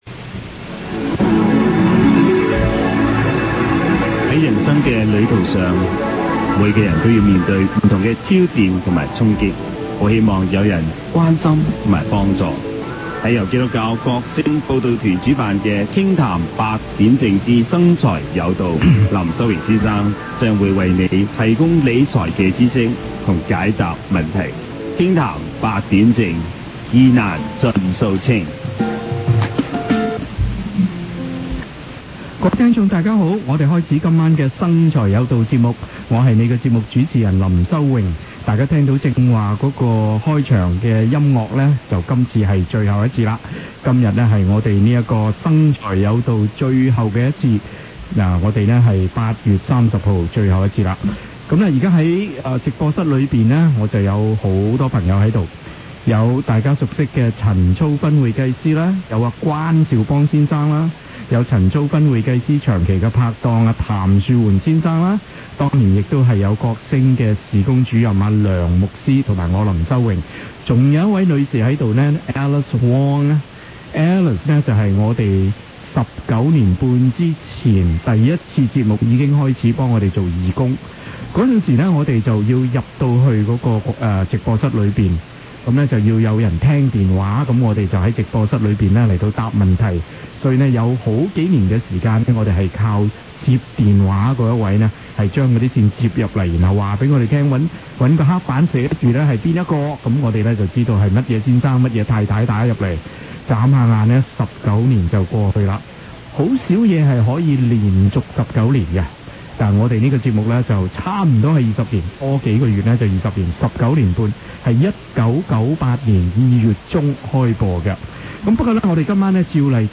我們一齊在播音室內做最後一次節目，雖然覺得有點感慨，但我們知道上帝會繼續使用我們，在其他的崗位上去服侍他。
last-wed-night-money-show.mp3